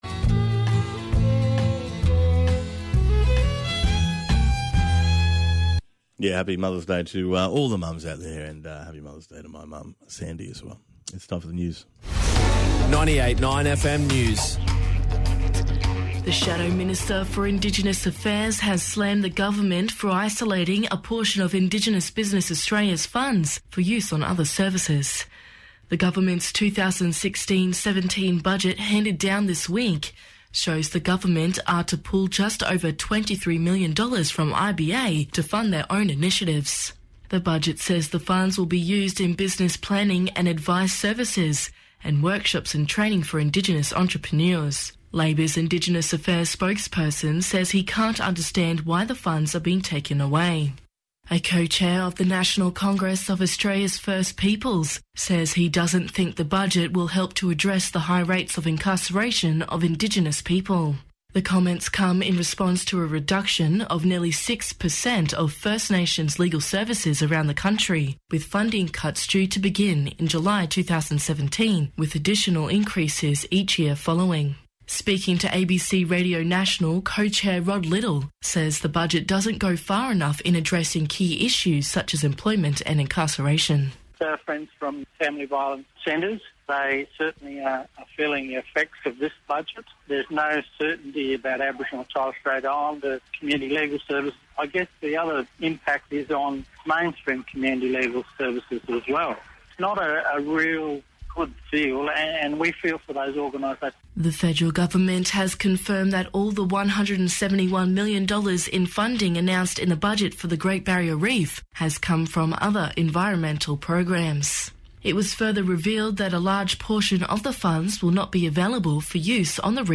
joined in the studio